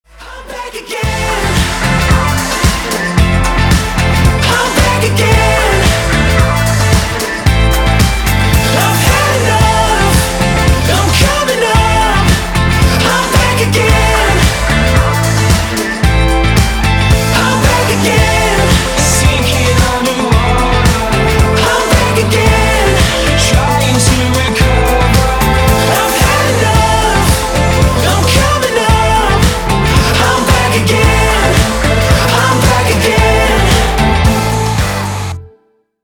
Задорная ROCK нарезка на гаджет